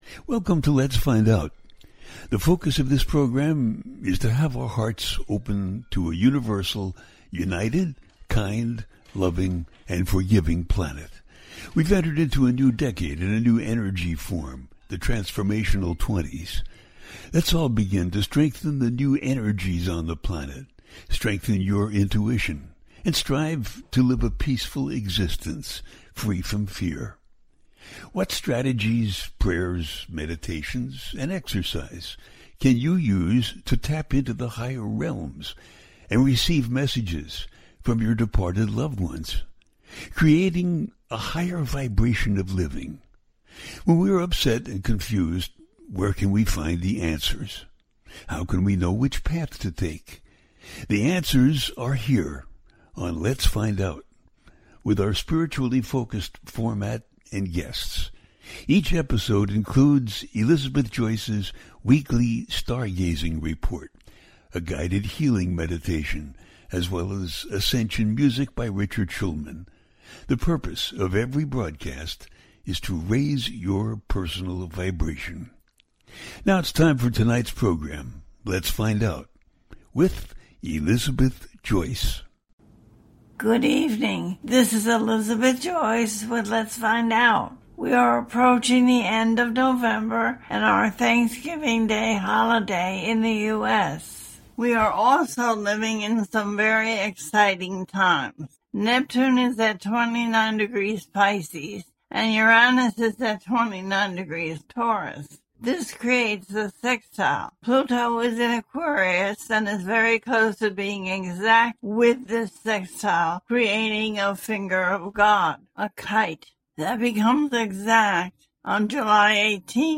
Scorpio New Moon And 3i Atlas - Week Of Nov 24 Update - A teaching show